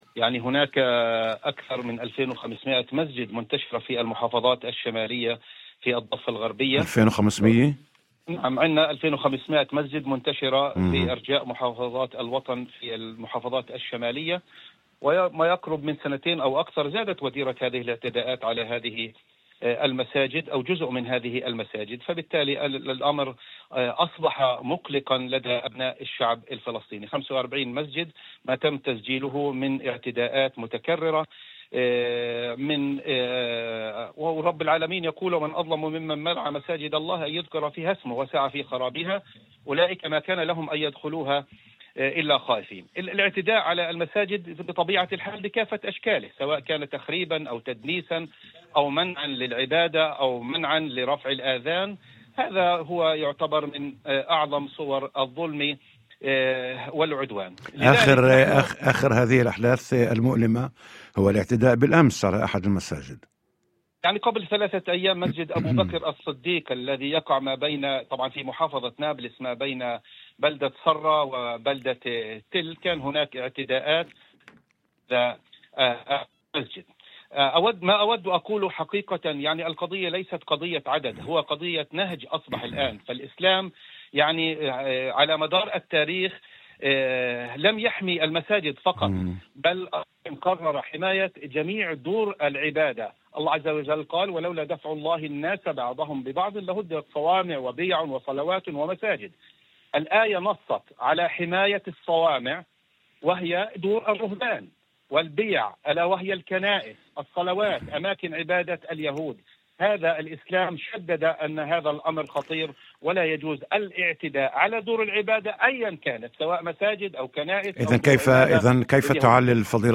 في مداخلة هاتفية ضمن برنامج "يوم جديد" على إذاعة الشمس